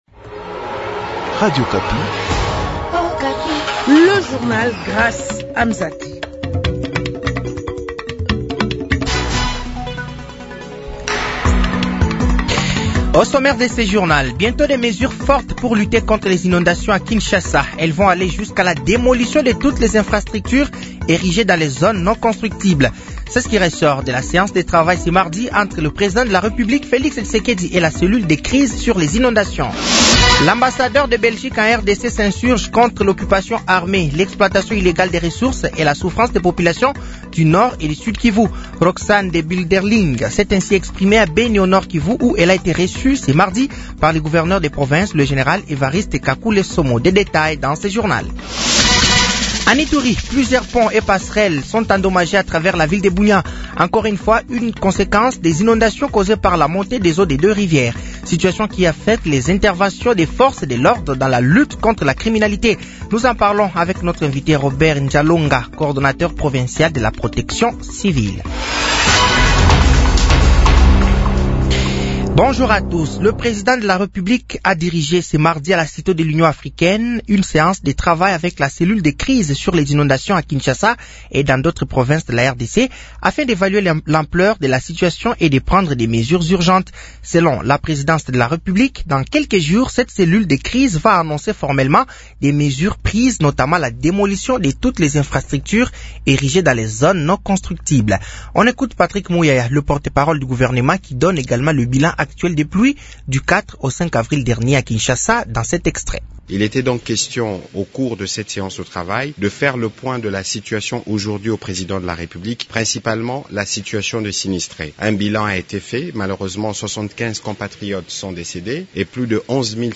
Journal français de 15h de ce mercredi 16 avril 2025